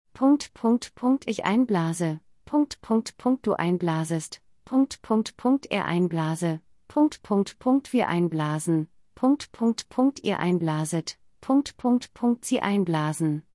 /ˈaɪnˌblaːzən/ · /blɛːst aɪn/ · /bliːs aɪn/ · /ˈbliːzə aɪn/ · /ˈaɪnˌɡəˈblasən/